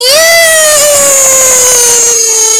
Silly Scream Sound Button - Free Download & Play